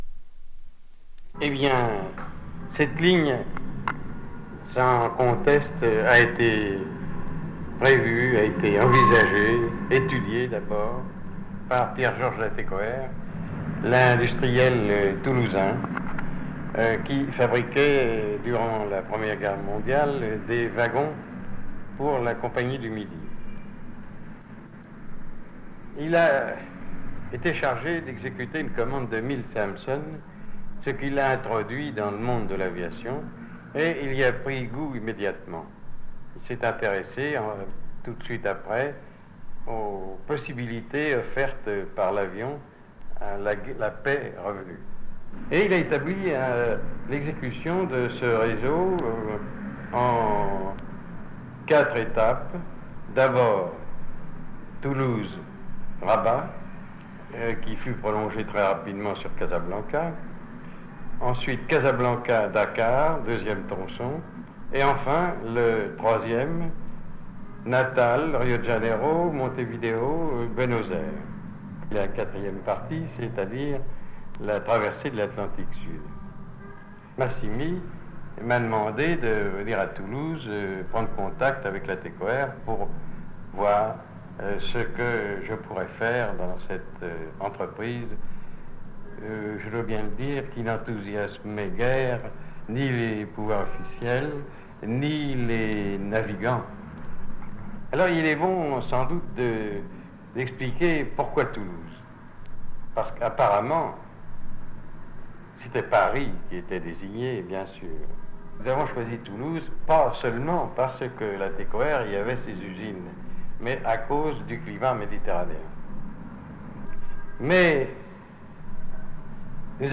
Cet enregistrement est constitué par les récits de onze témoins qui vécurent entre 1918 et 1933, la conquête aérienne de l’Atlantique Sud